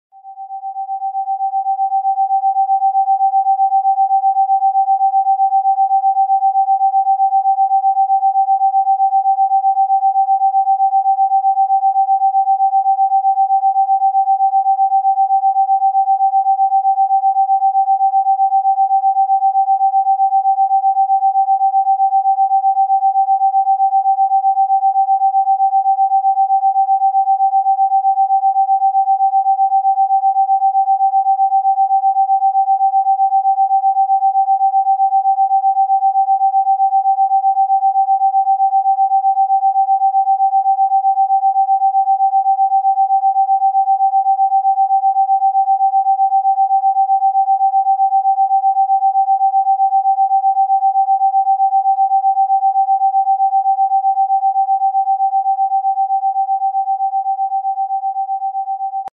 777Hz Frequency 🌊 | Pulses sound effects free download